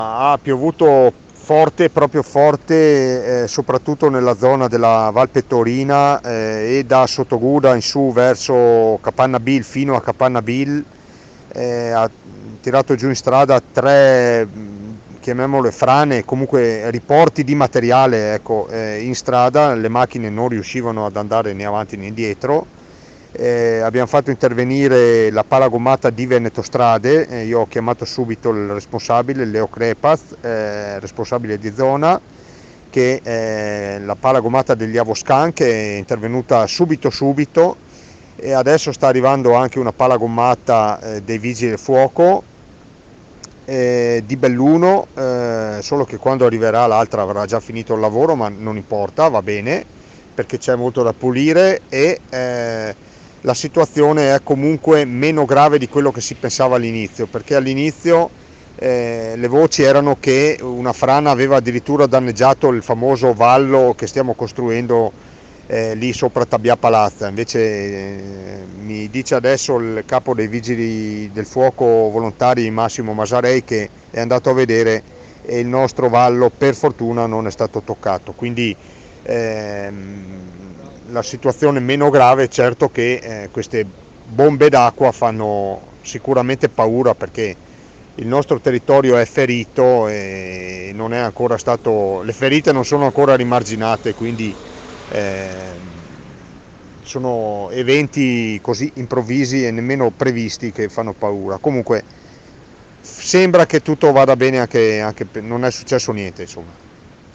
IL SINDACO DI ROCCA PIETORE ANDREA DE BERNARDIN